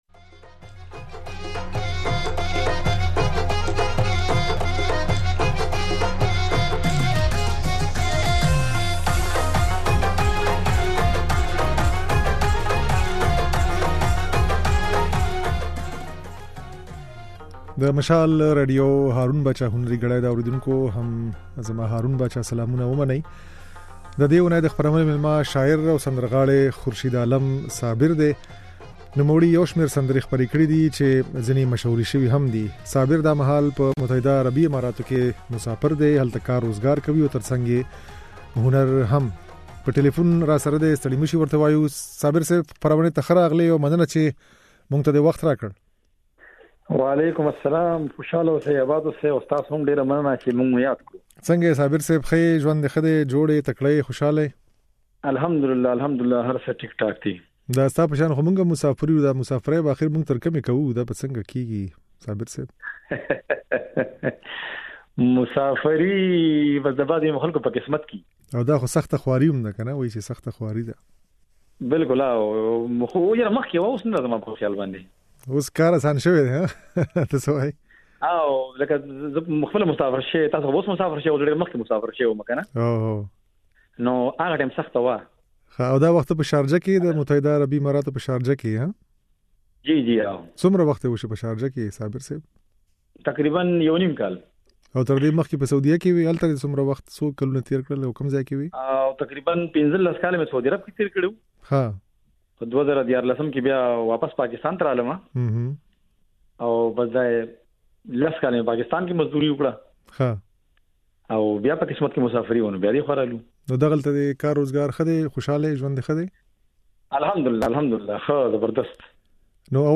خپرونې مېلمه شاعر او سندرغاړی